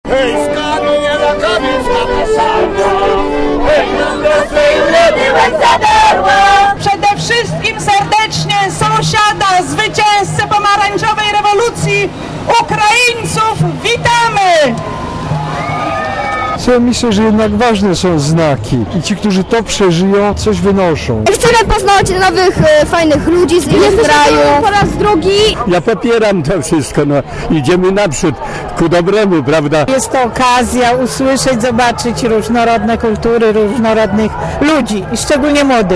Mówią uczestnicy parady